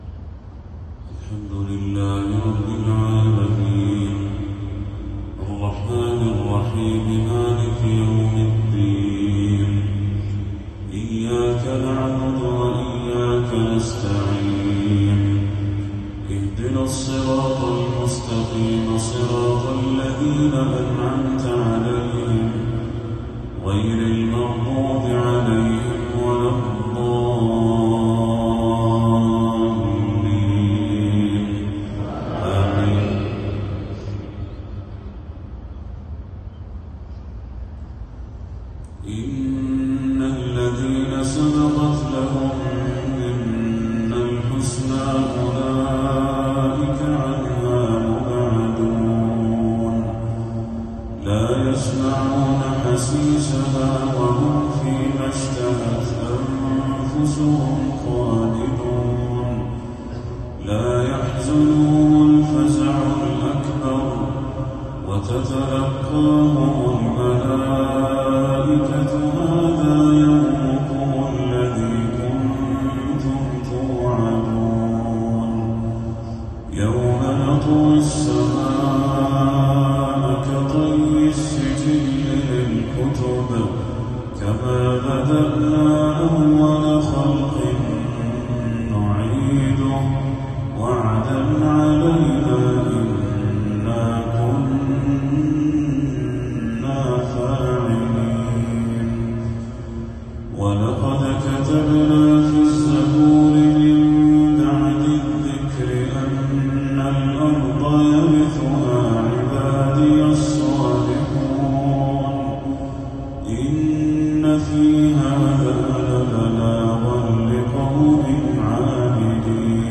تلاوة عذبة لخواتيم سورتي الأنبياء والحج للشيخ بدر التركي | عشاء 15 صفر 1446هـ > 1446هـ > تلاوات الشيخ بدر التركي > المزيد - تلاوات الحرمين